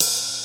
• 1970s Soft Open Hat Sound D# Key 11.wav
Royality free open hat tuned to the D# note. Loudest frequency: 6758Hz